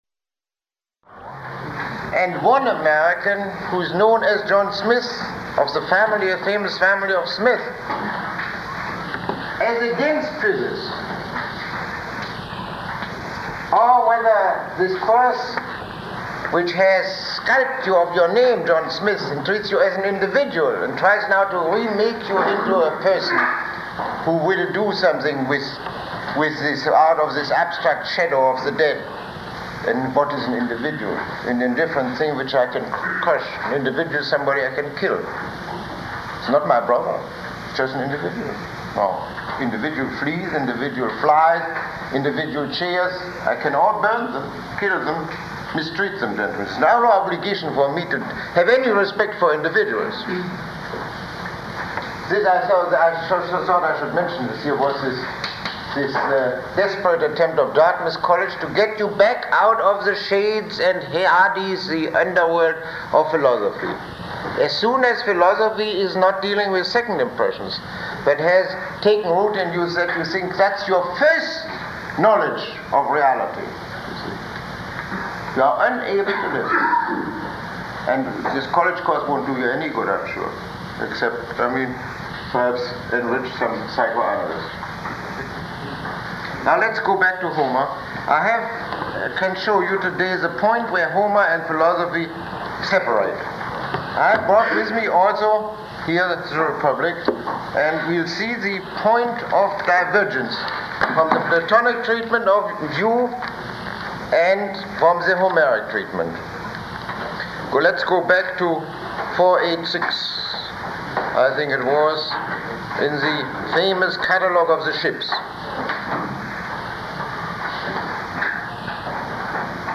Lecture 06